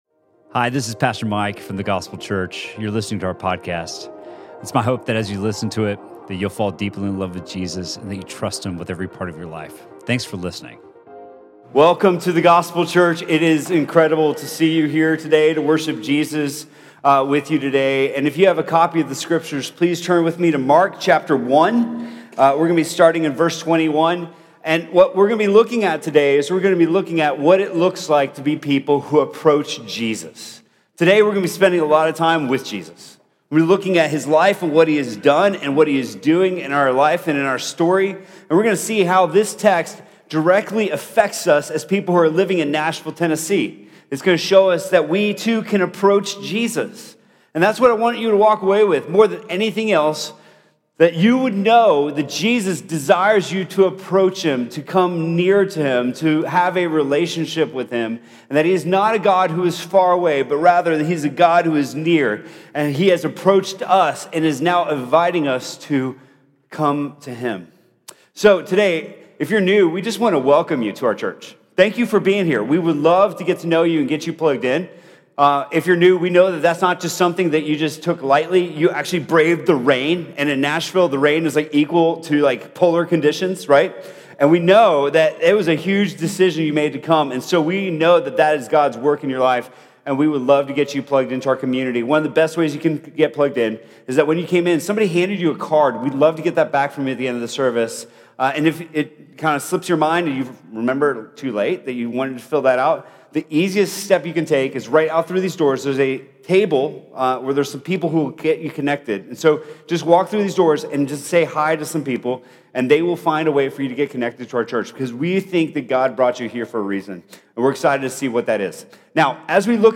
Sermon from The Gospel Church on February 17th, 2019.